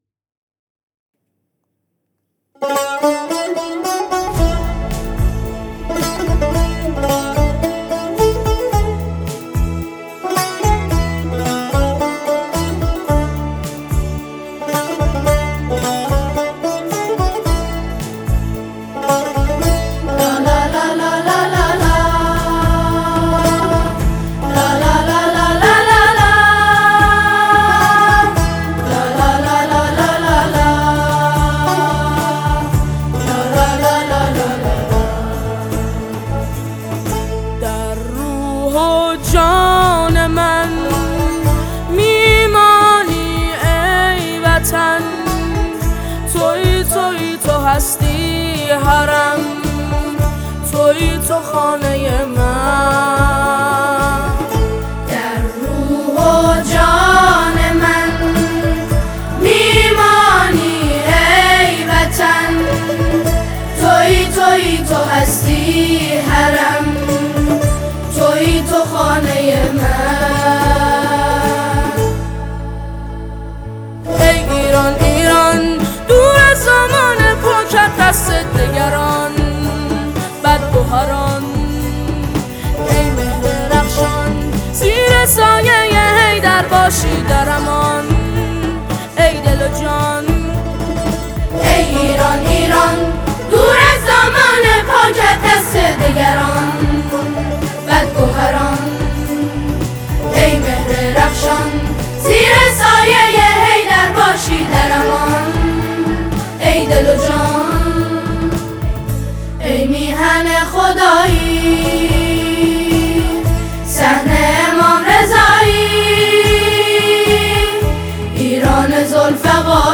با اجرای پاتوقی و همخوانی
ژانر: سرود